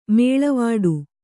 ♪ mēḷavāḍu